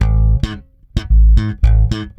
-AL DISCO A#.wav